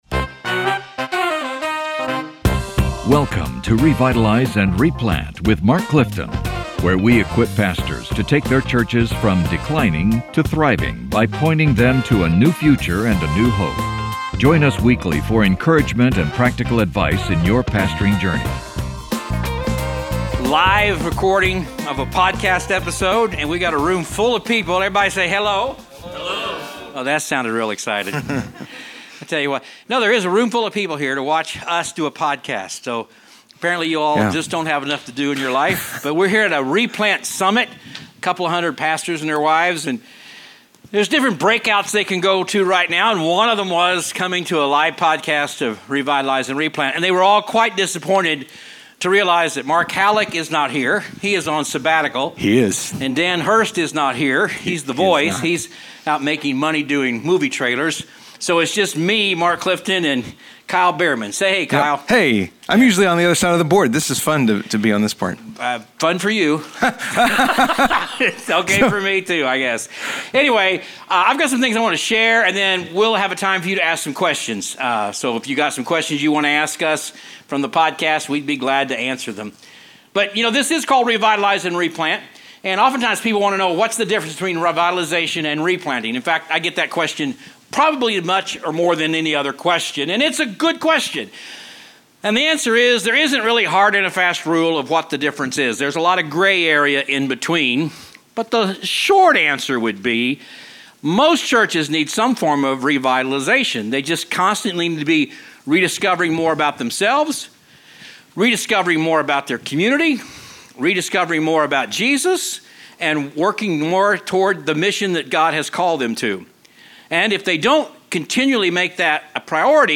This episode was recorded live at the 2024 Replant Summit.